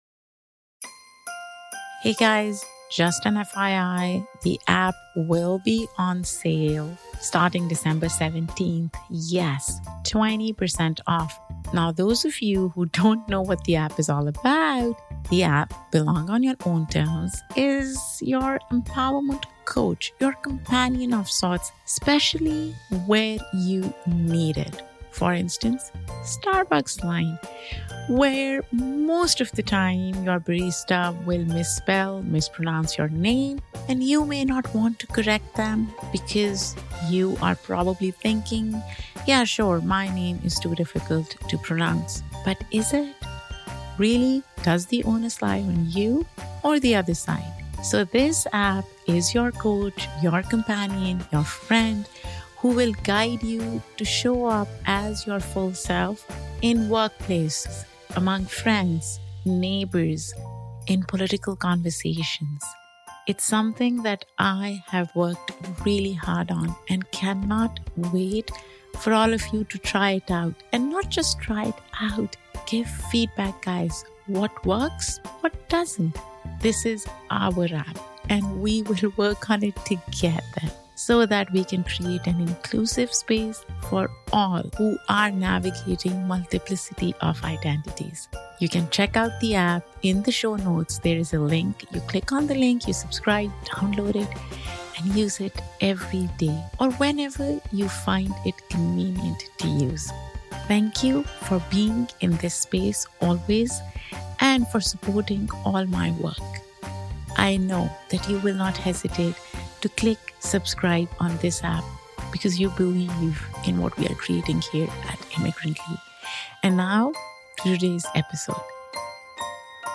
In this solo year-end episode